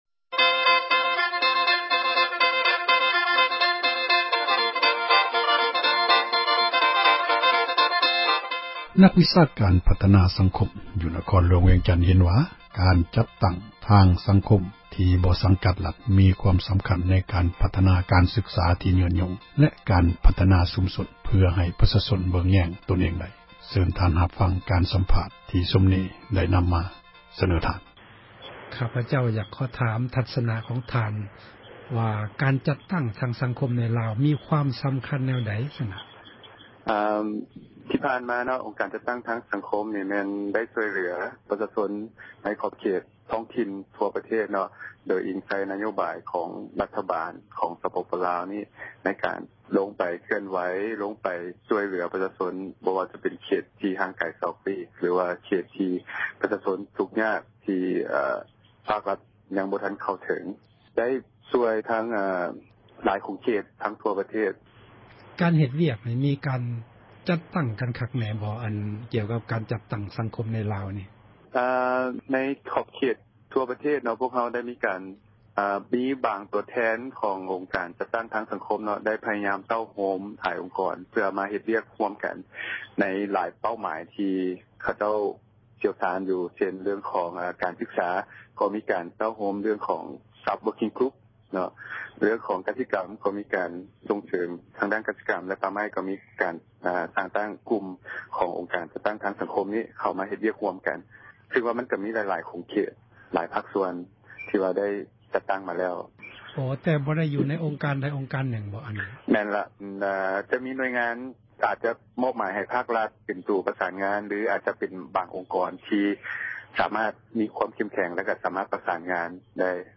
ສໍາພາດນັກວິຊາການພັທນາ ສັງຄົມ ທີ່ວຽງຈັນ